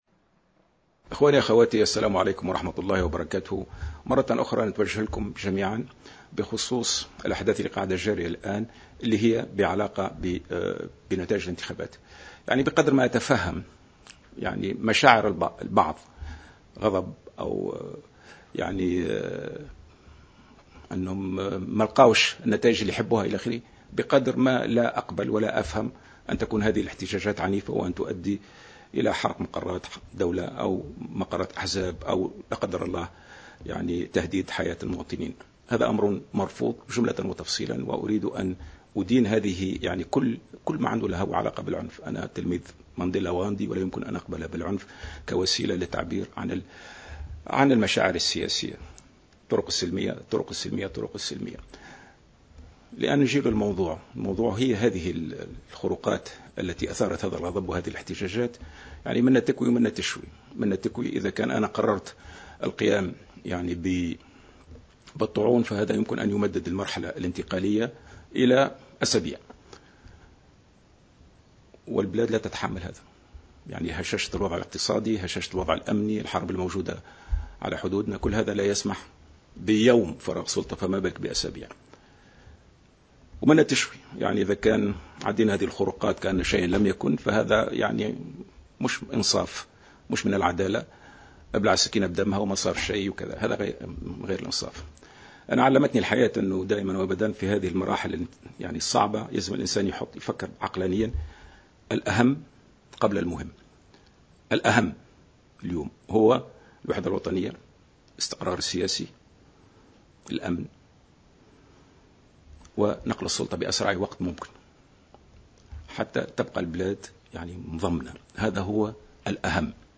قرر رئيس الجمهورية المنتهية ولايته في كلمة ألقاها اليوم الاربعاء العدول عن تقديم الطعون ولو على مضض، كما قرر التسريع في نقل السلطة في أقرب وقت وهو ما سيحصل يوم الثلاثاء.